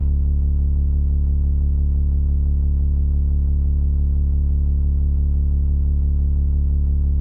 Shady Aftermath Long Bass.wav